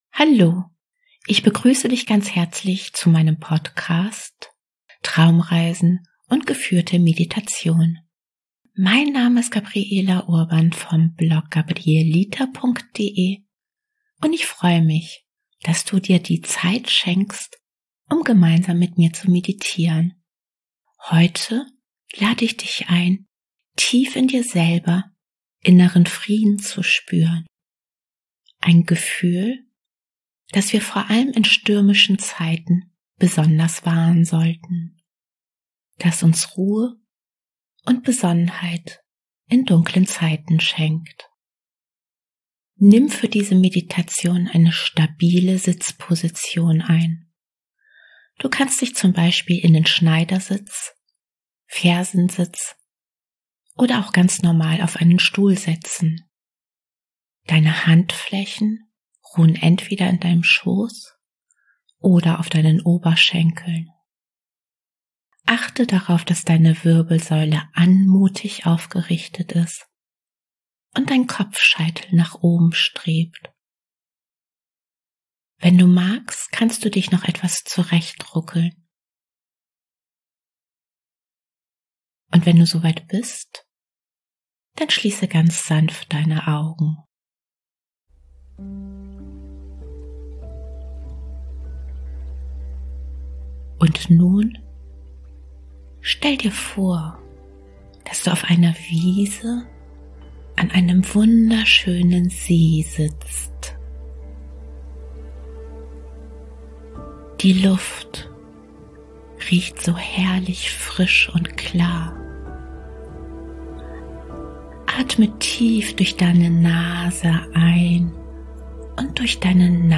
#061: Meditation inneren Frieden finden